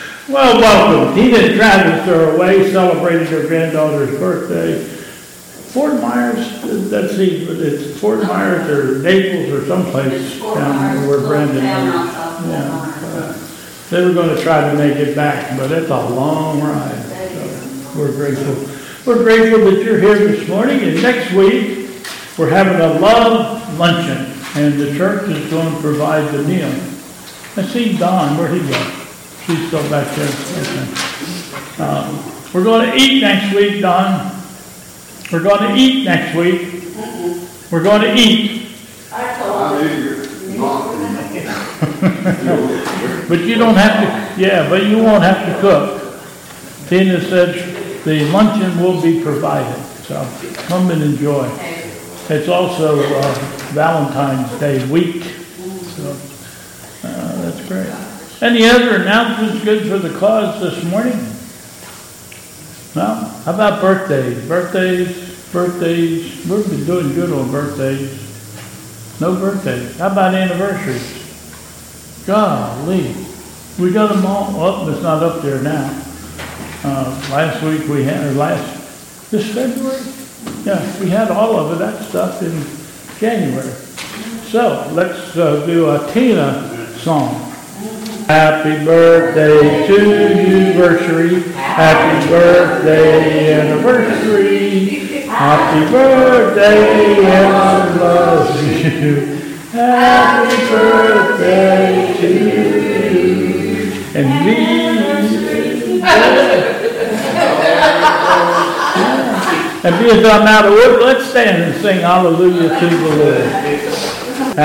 Bethel Church Service
Welcome and Announcements...